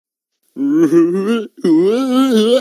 Sound Effects
Yellow Face Weird Noise